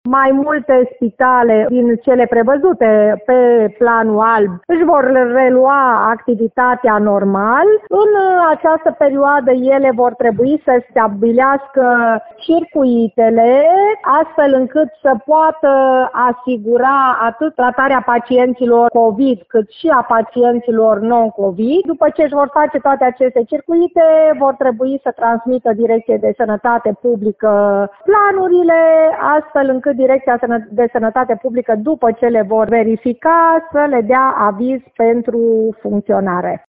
Prefectul Liliana Oneț spune însă că pentru reluarea activității normale este nevoie de realizarea unor circuite separate și de un aviz din partea DSP.